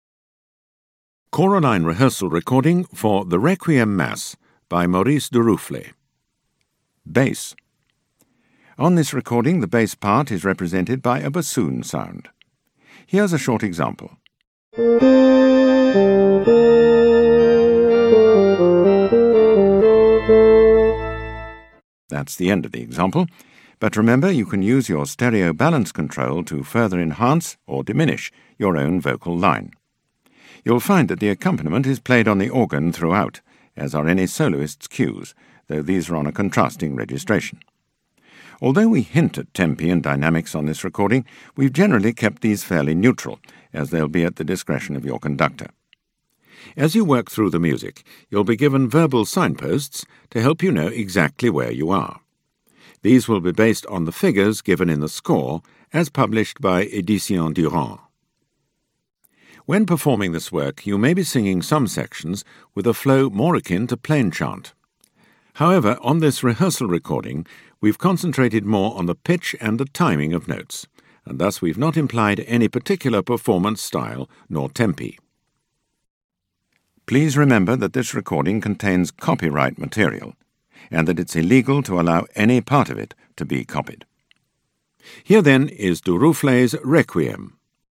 1st Bass